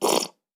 Blood_Squirt_53.wav